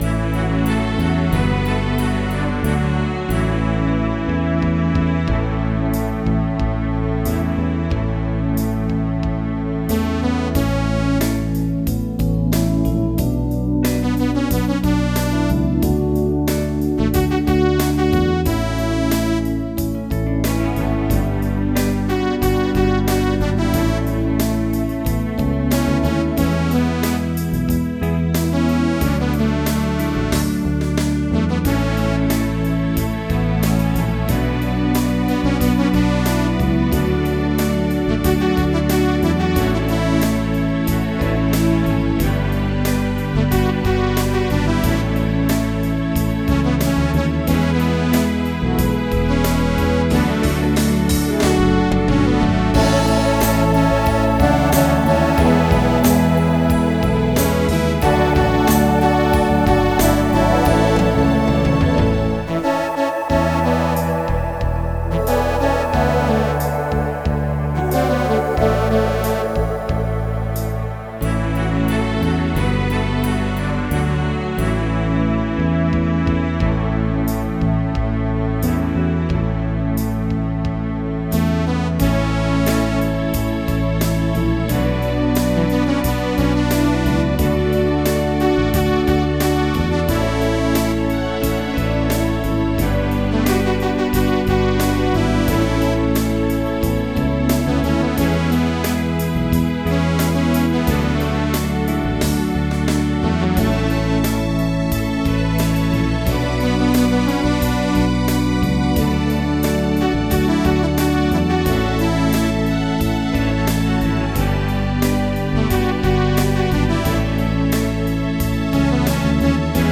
Pop
MIDI Music File
Type General MIDI